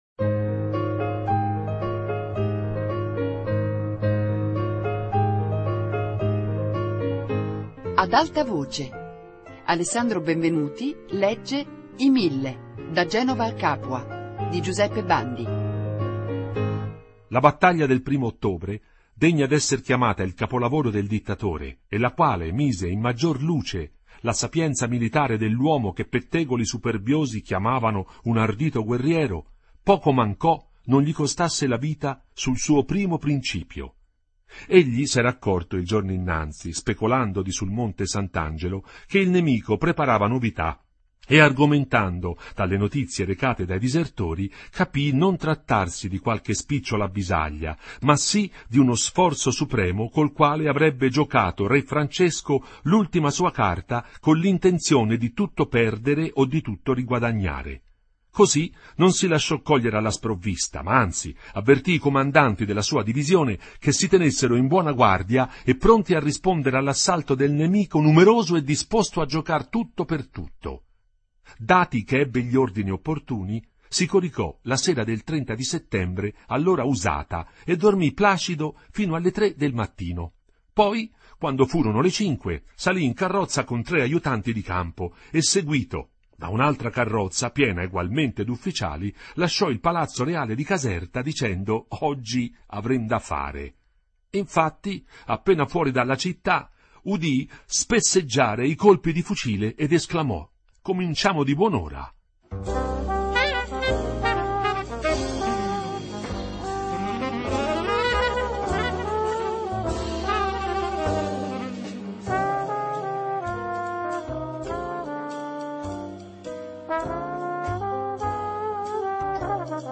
I mille da Genova a Capua - Lettura XVII